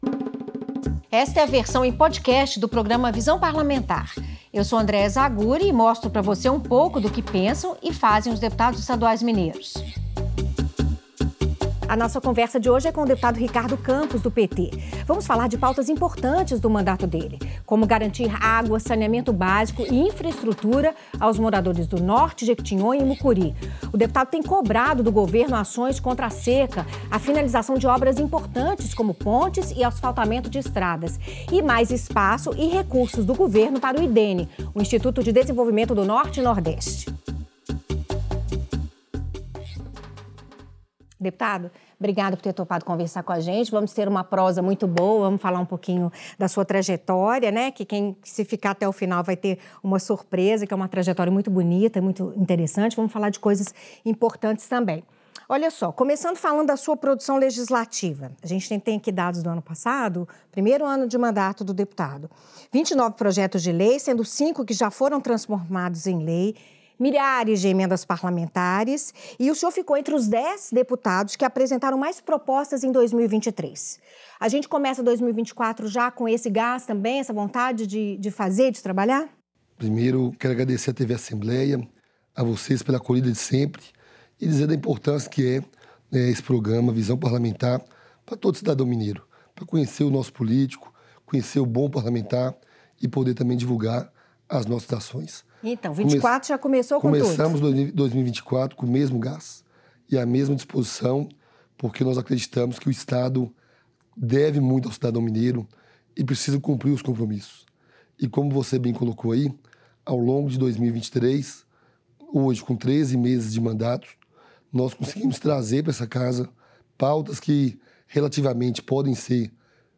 No programa Visão Parlamentar, o deputado Ricardo Campos (PT) faz críticas à Cemig e defende a ampliação da capacidade energética da empresa no Jequitinhonha e no Norte e Noroeste de Minas. O parlamentar também pede investimentos do Estado para garantir o armazenamento de água e a recuperação de rios, reduzindo os efeitos da seca no semiárido mineiro. Na entrevista, o deputado defende ainda que o Instituto de Desenvolvimento do Norte e Nordeste de Minas (Idene) receba mais recursos do governo estadual e tenha uma atuação estratégica, fomentando o desenvolvimento das regiões atendidas. Ricardo Campos também critica a interrupção da obra de uma ponte que conecta os municípios de São Francisco e Pintópolis, no Norte de Minas.